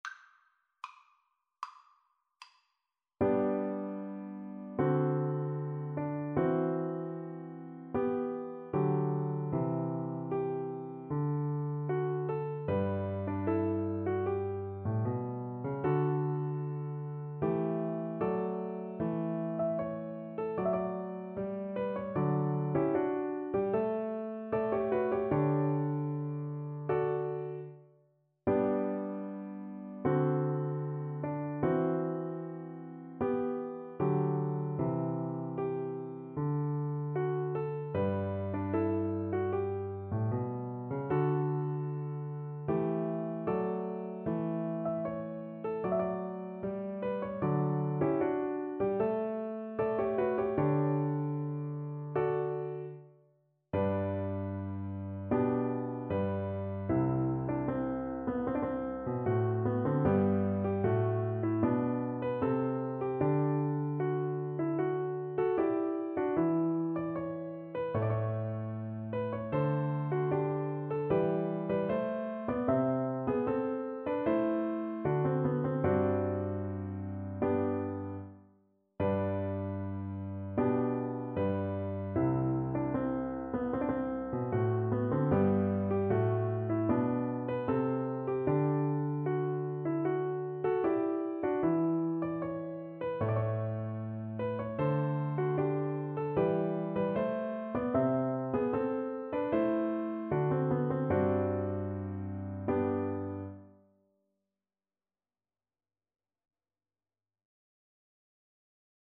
Andante =76
4/4 (View more 4/4 Music)
Classical (View more Classical Viola Music)